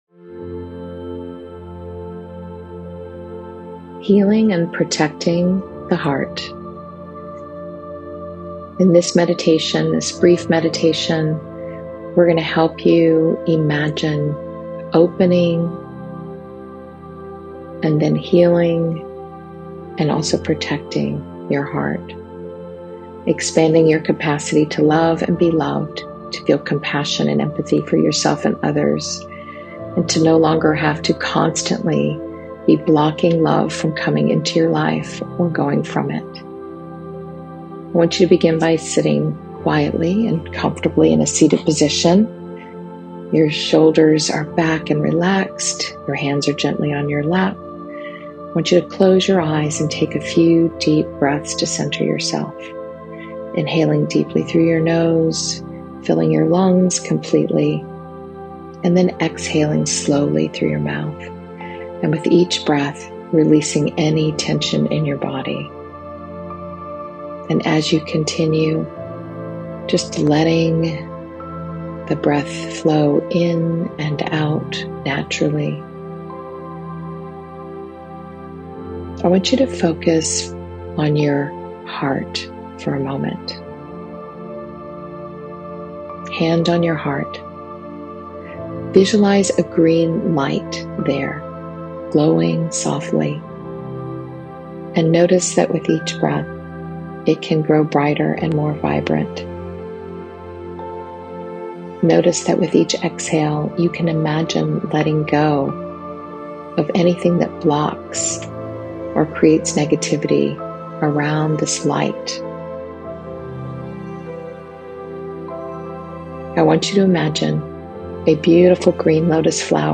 This visualization helps you recognize and feel the presence of your support system, both seen and unseen.